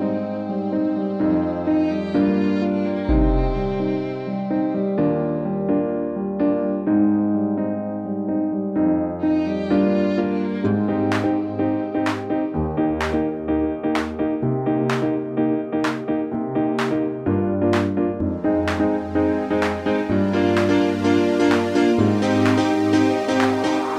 Minus Main Guitar Pop (2010s) 3:22 Buy £1.50